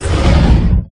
Push.ogg